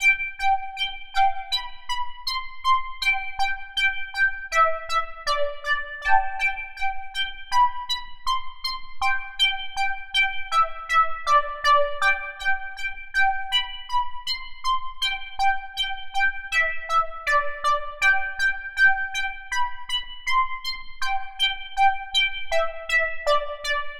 Synth 80 Bpm .wav